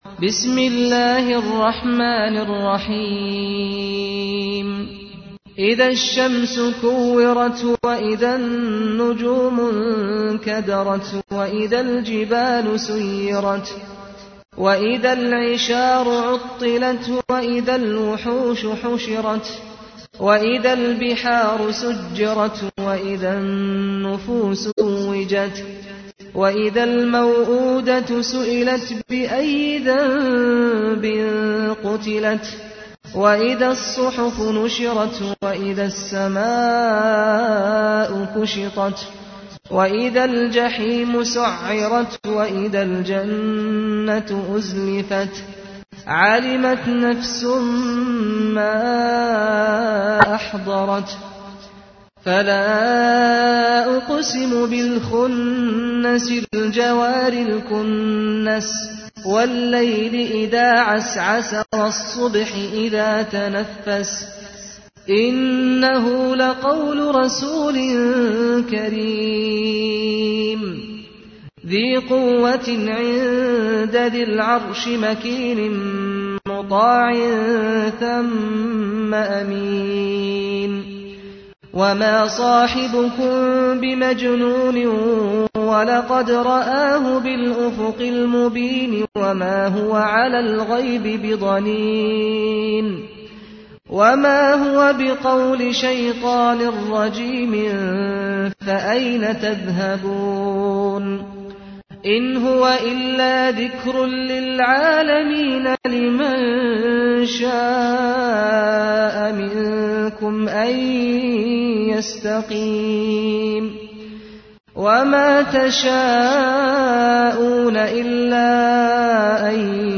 تحميل : 81. سورة التكوير / القارئ سعد الغامدي / القرآن الكريم / موقع يا حسين